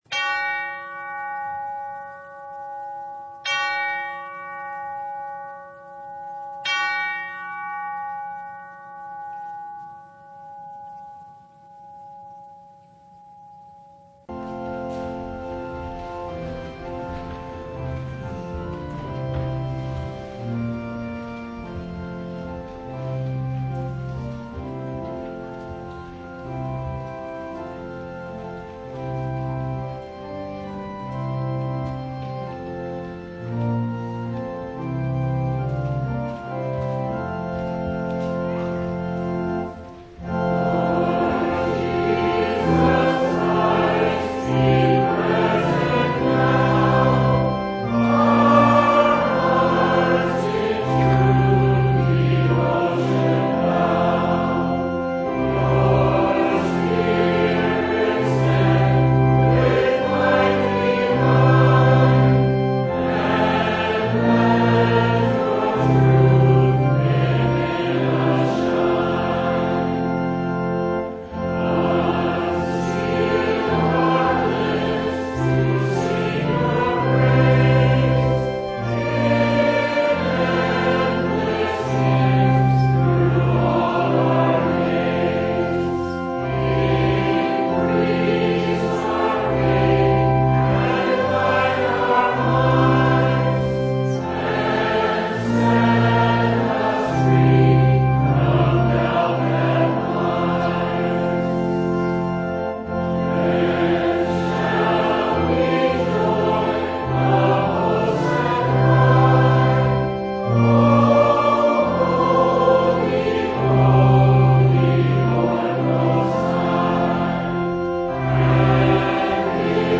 Download Files Notes Bulletin Topics: Full Service « Sermon from Fifth Sunday in Martyrs’ Tide (2023) How Much Forgiveness?!